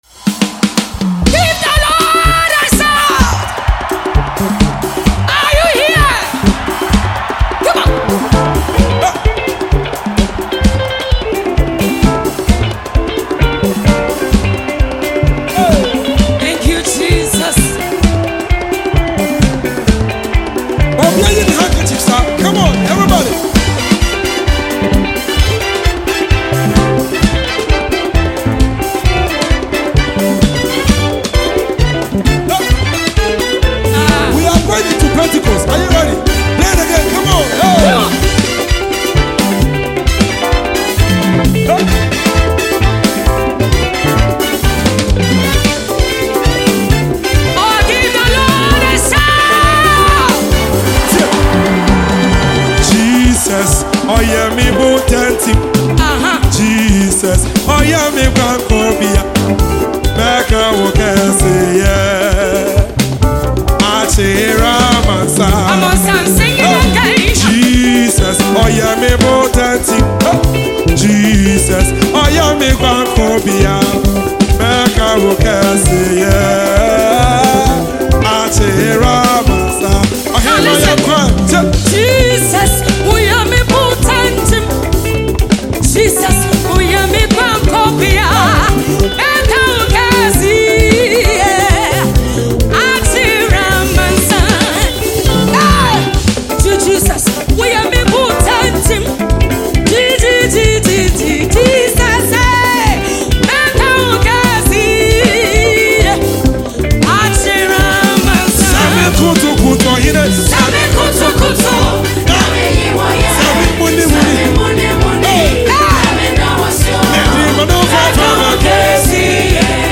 January 17, 2025 Publisher 01 Gospel 0
contemporary gospel